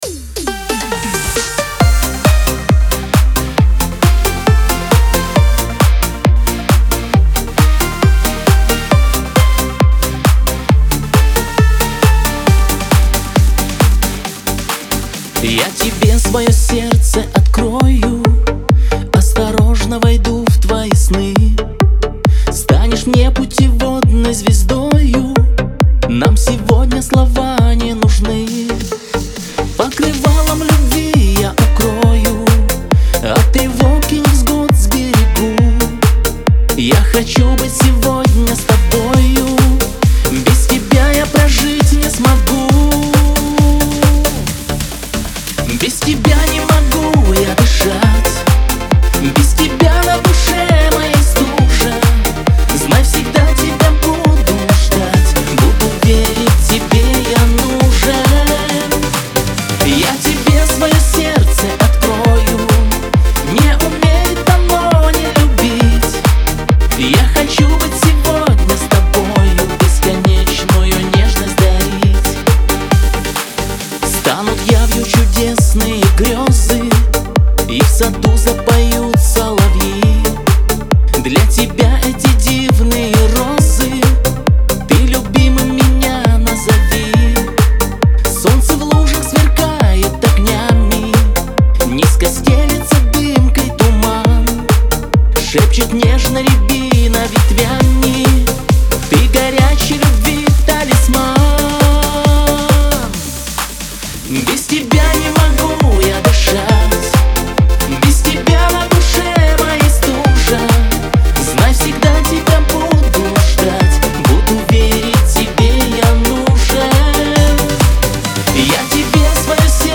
dance , pop
эстрада , диско